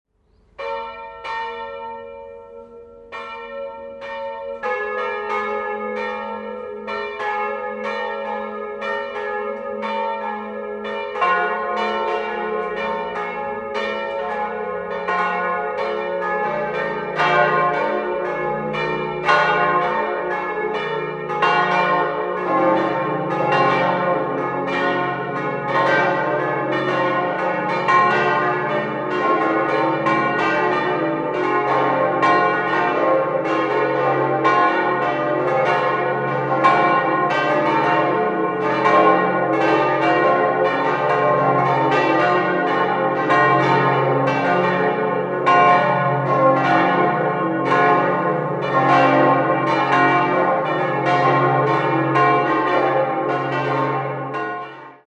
5-stimmiges Geläute: des'-es'-f'-as'-b'
Die Glocken stammen aus der Gießerei Hamm/Regensburg und sind auf die Töne des'-4, es'-4, f'+2, as'+2 und b'-2 gestimmt.
bell
Die Schlagtonlinie ist deutlich hörbar unsauber: zwischen den drei mittleren Glocken hört man fast einen Tritonus.